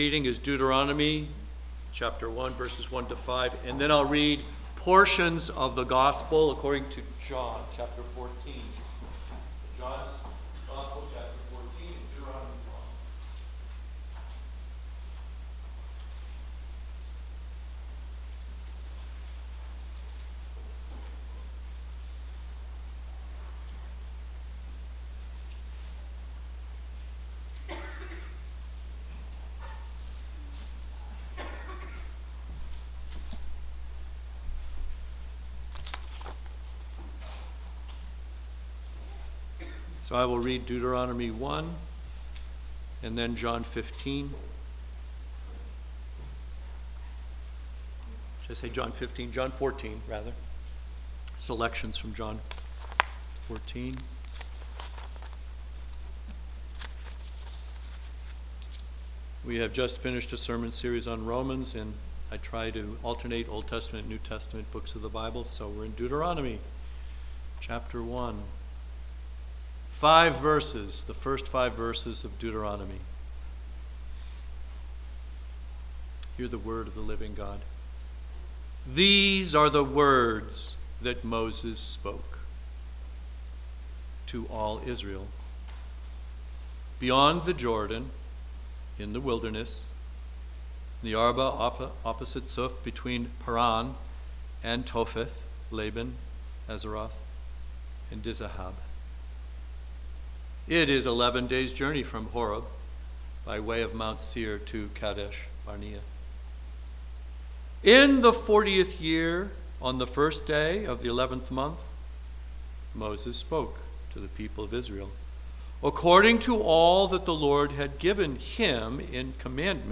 A Sermon from Deuteronomy 1:1-5
Service Type: Sunday Morning